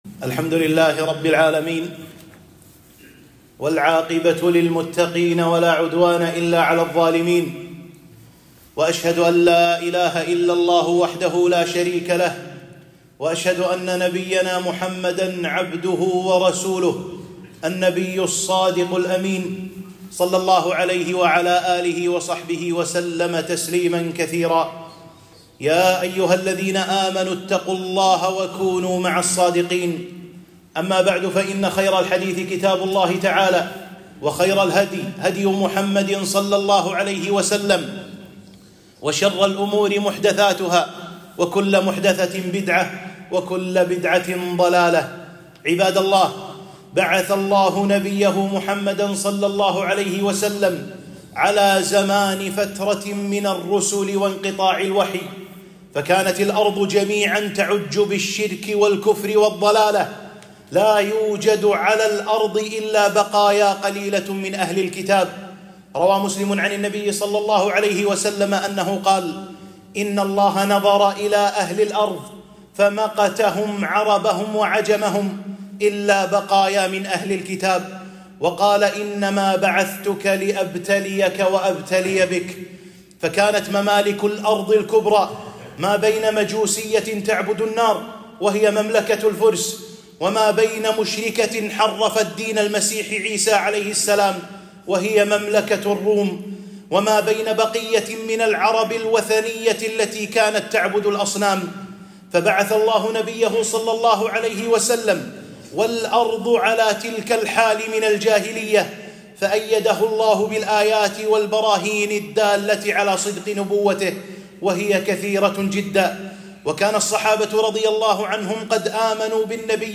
خطبة - قصة إسلام سلمان الفارسي رضي الله عنه